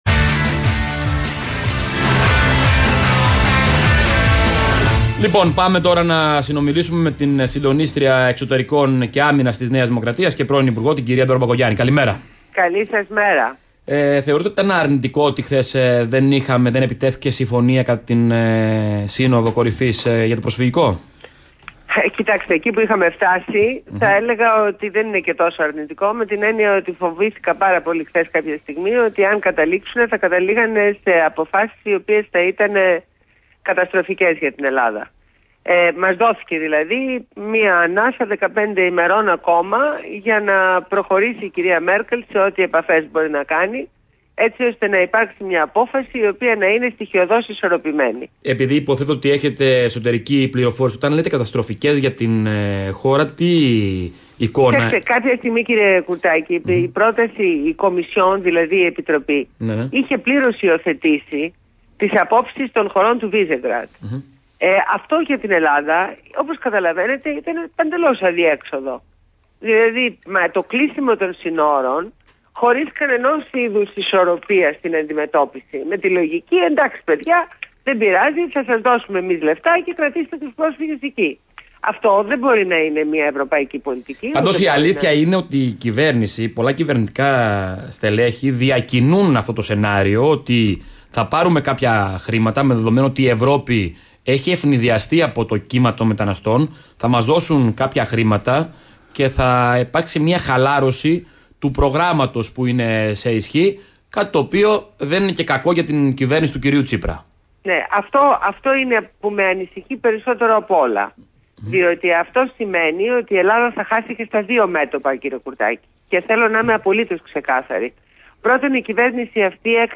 Συνέντευξη στο ραδιόφωνο Παραπολιτικάfm 90,1.